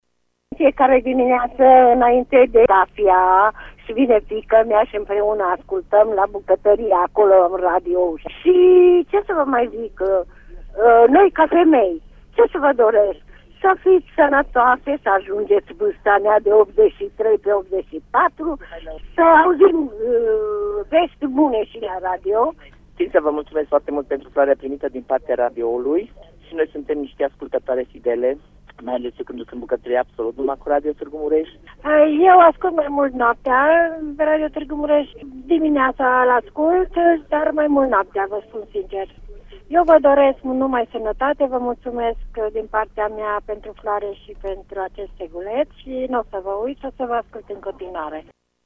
Impresionate de gestul colegilor noștri, doamnele au recunoscut că sunt ascultătoare fidele ale Radio Tîrgu Mureș:
vox-femei.mp3